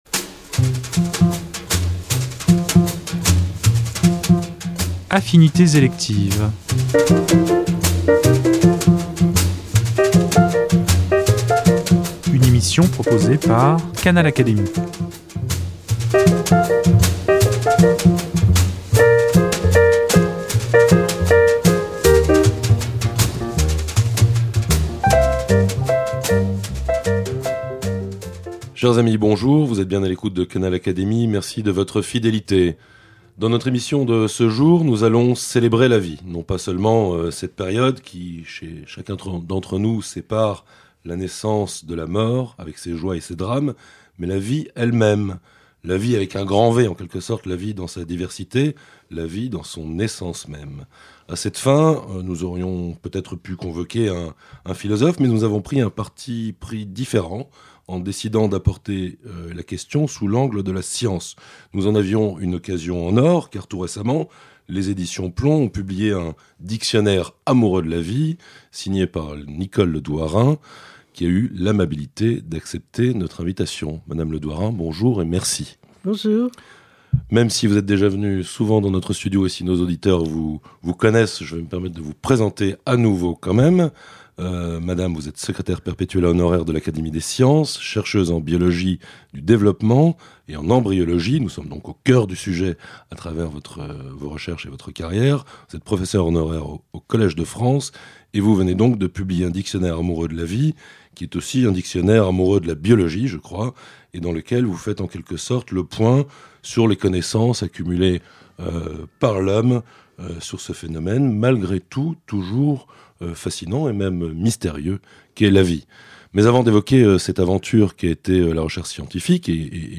Au terme de cet entretien, c’est cependant un inextinguible sentiment d’émerveillement qui nous étreint devant le « miracle » de cette vie apparue sur terre il y a quelque 3800 millions d’années.